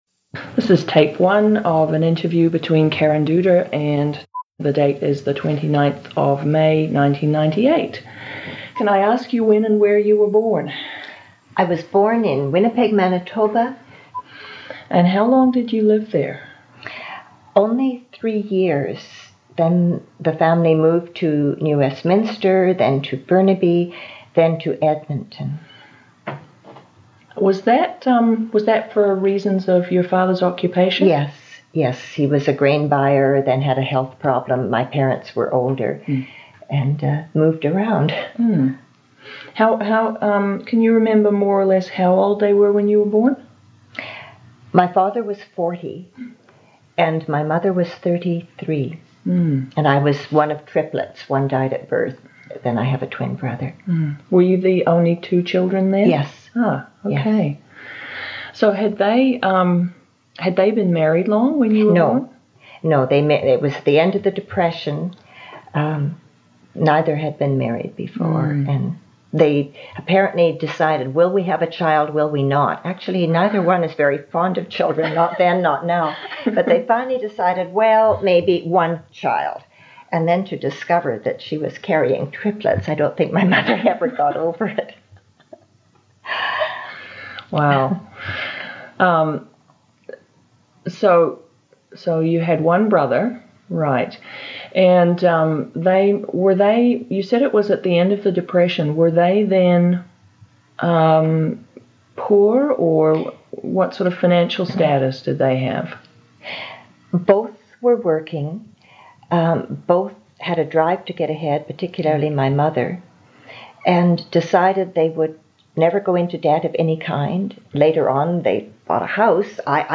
Lesbian and Bisexual Women in English Canada audio history collection